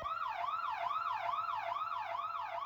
siren.wav